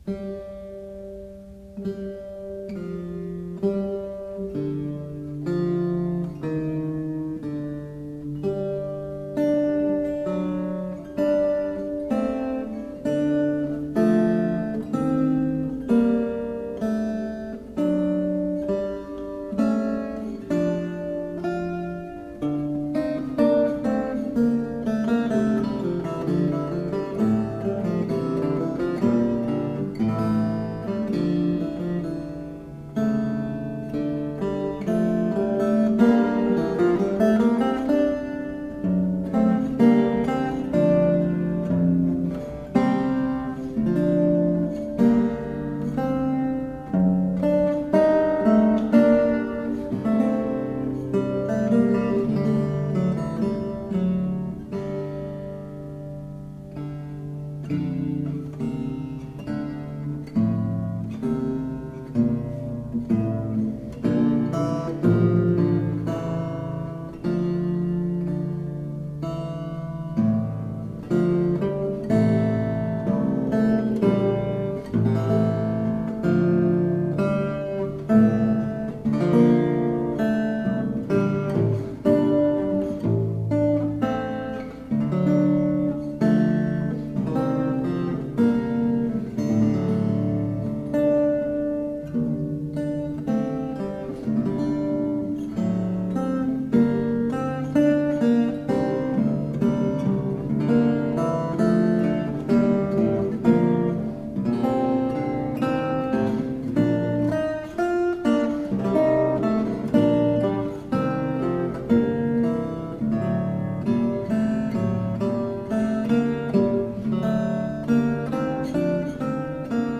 Renaissance lute.
Bandora
Classical, Renaissance, Instrumental